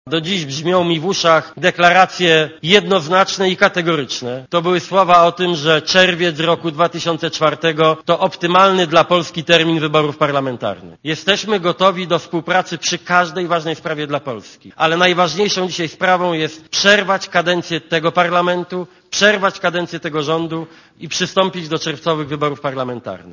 Platforma Obywatelska domaga się przyśpieszonych wyborów parlamentarnych w czerwcu br. - taką deklarację złożył szef ugrupowania Donald Tusk podczas posiedzenia w Warszawie Rady Krajowej PO.
Mówi Donald Tusk (90,6 KB)